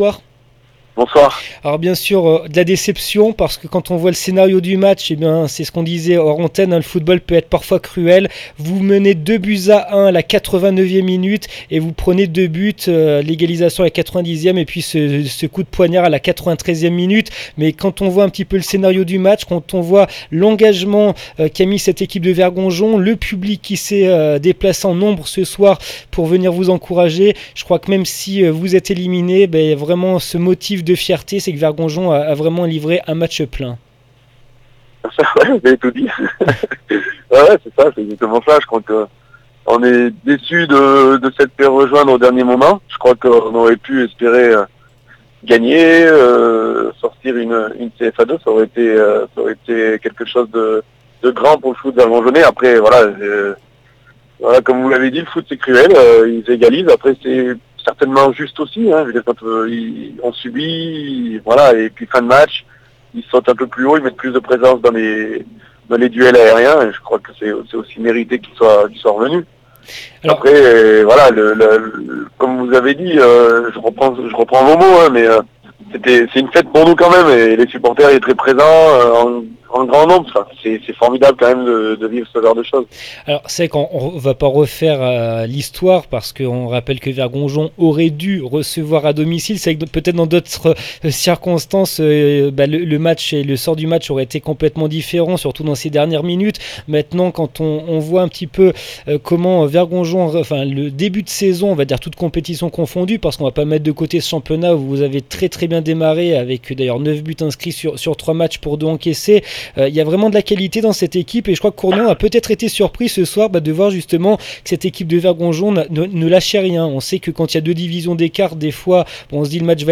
9 octobre 2016   1 - Sport, 1 - Vos interviews, 2 - Infos en Bref   No comments